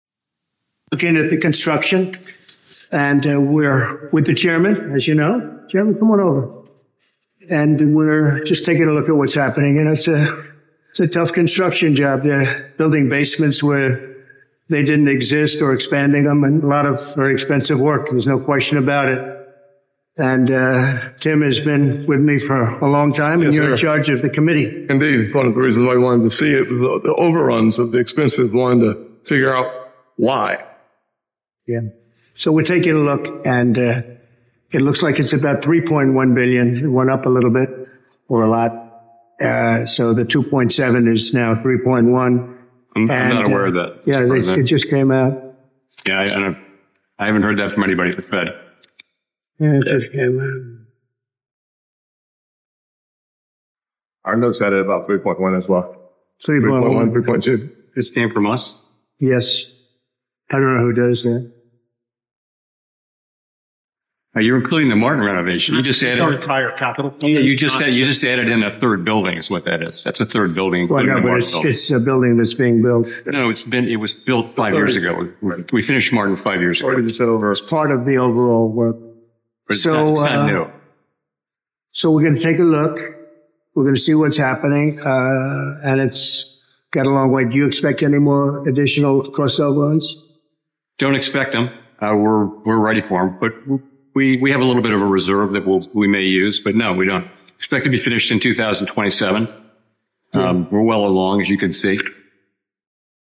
Exchange with the U.S. President on the Renovation of Federal Reserve Buildings
delivered 24 July 2025, Washington, D.C.
Audio Note: AI noise reduction and other digital enhancements used for clarity